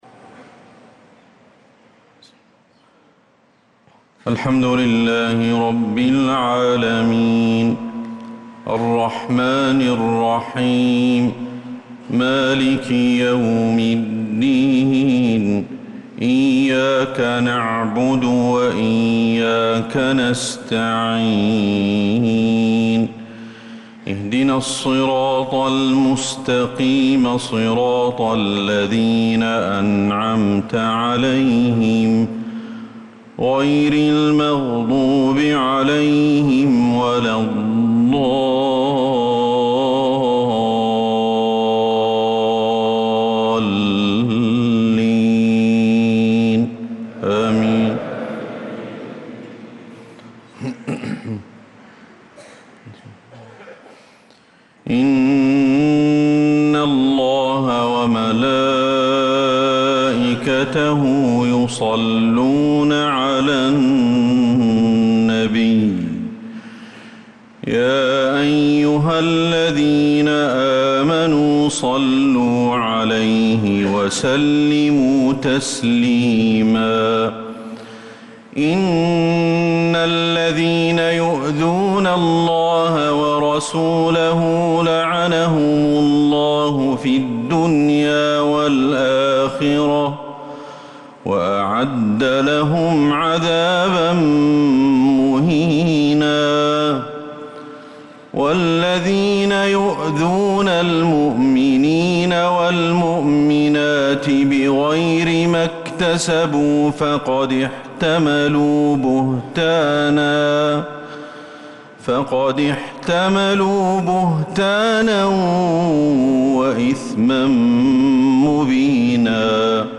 الفروض
تِلَاوَات الْحَرَمَيْن .